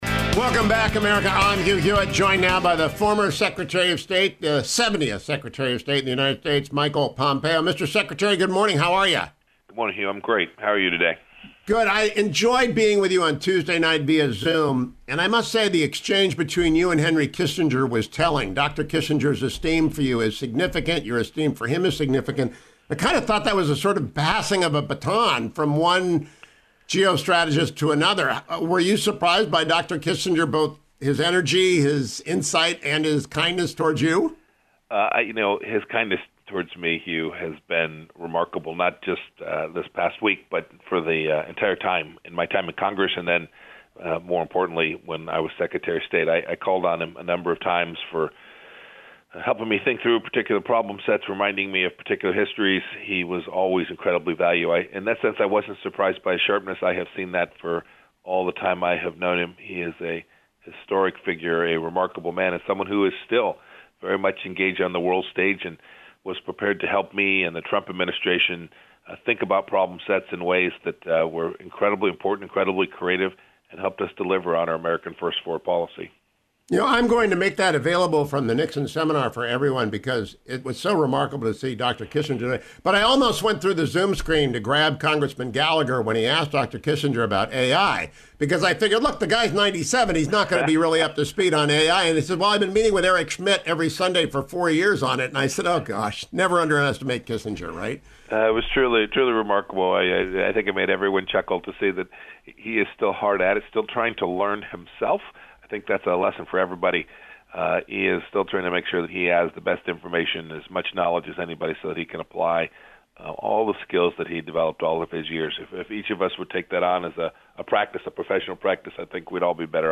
Former Secretary of State Mike Pompeo joined me Friday morning: